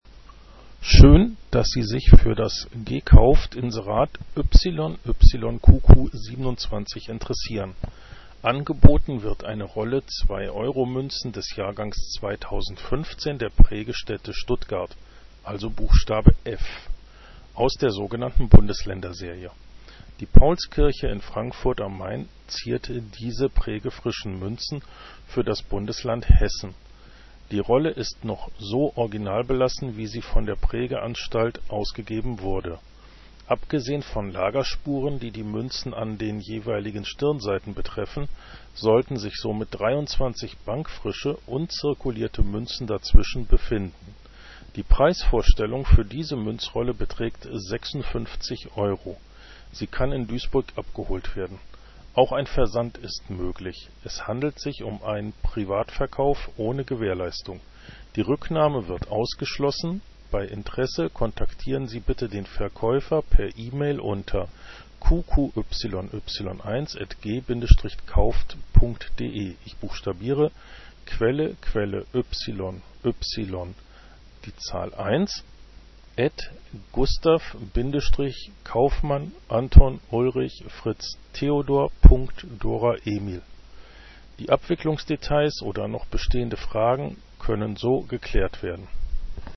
VerkaufsRadioClip_2_Euro_Münzrolle_2015_F.mp3